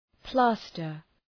{‘plæstər}